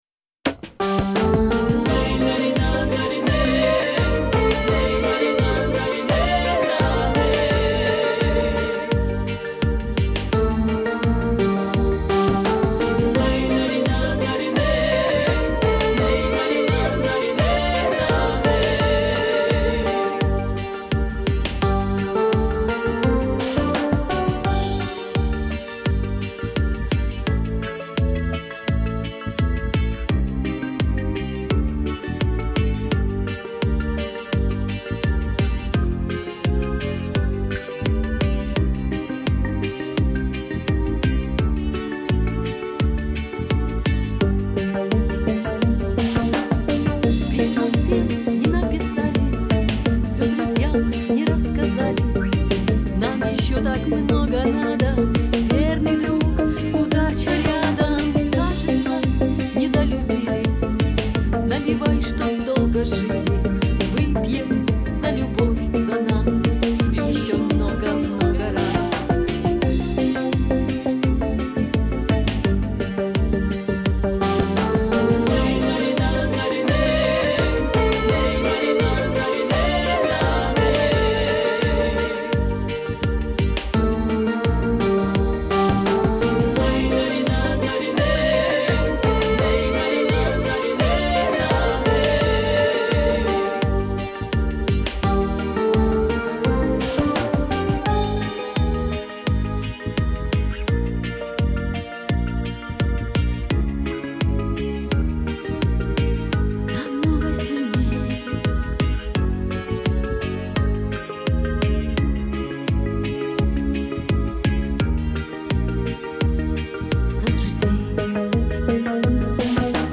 Пойте караоке